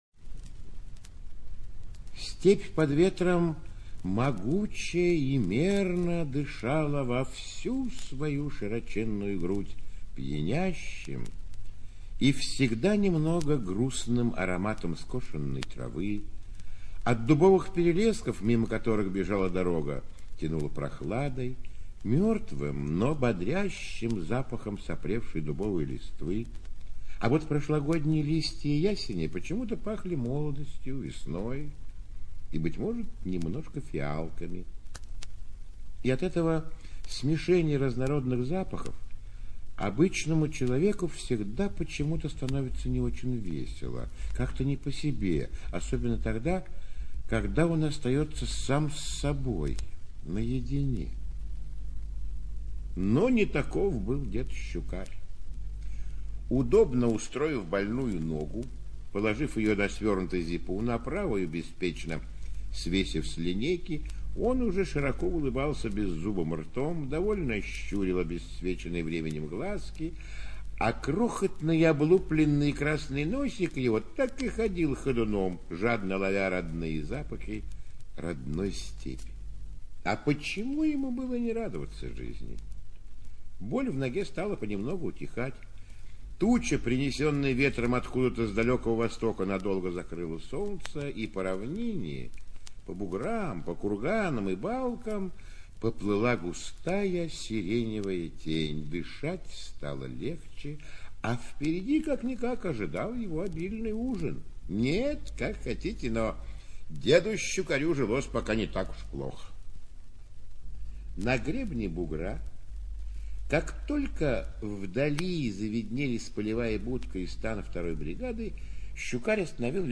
ЧитаетГрибов А.